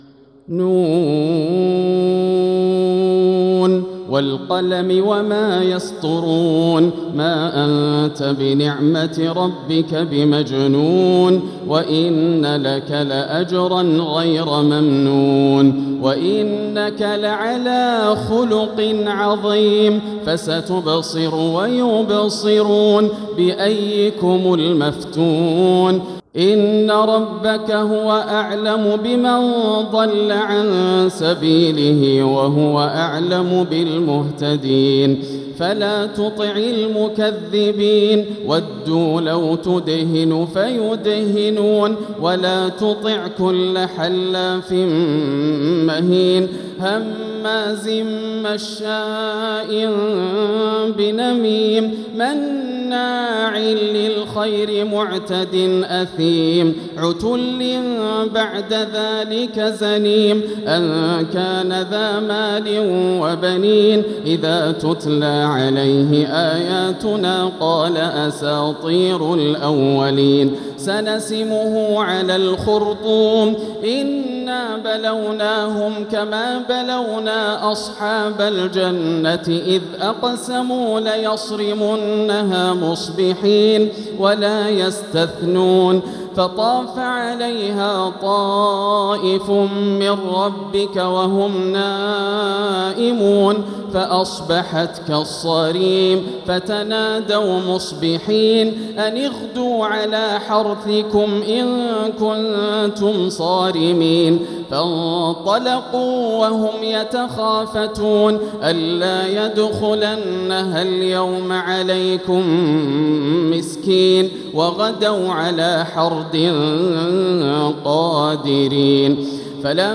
سورة القلم | مصحف تراويح الحرم المكي عام 1446هـ > مصحف تراويح الحرم المكي عام 1446هـ > المصحف - تلاوات الحرمين